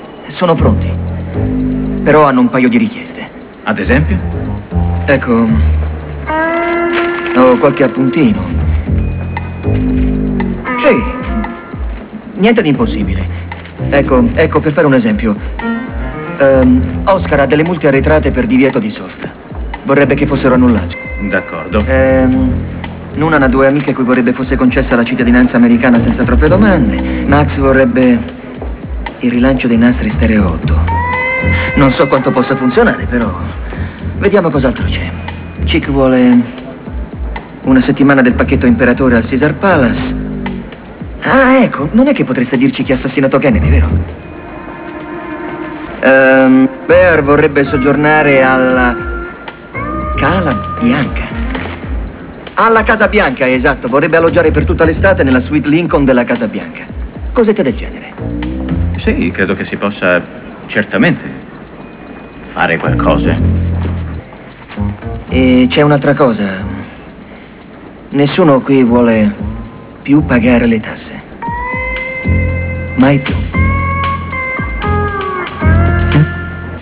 Frase celebre